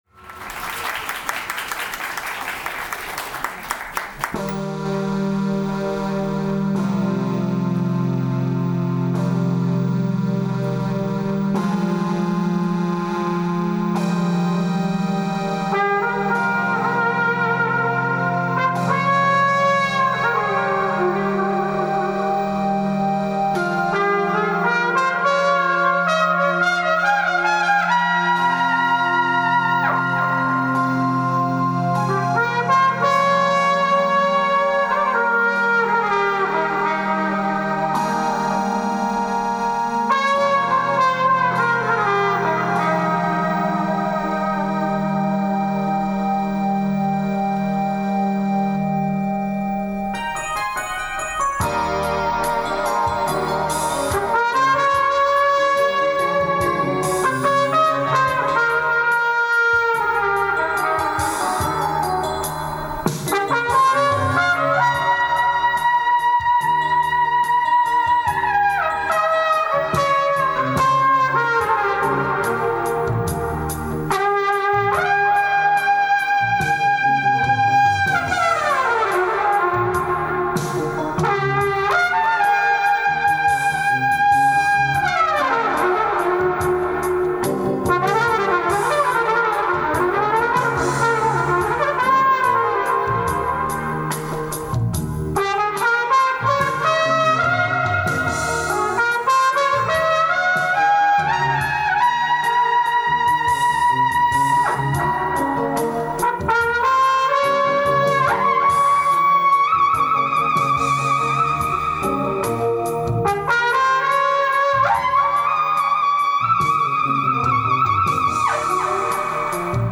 今日は、我家から車で1時間ほどの福岡市内某所の老人クラブ連合会が催され、お声掛けをいただき、演奏に行ってきました。
僕のトランペット
という構成で、僕はたっぷり1時間のお時間をいただいて、60数名のお客様の前で演奏を楽しませていただきました。
僕の音（実況録音）
実況録音聴かせていただいて、会場のリラックスしたとても良い雰囲気が伝わってきましたよ♪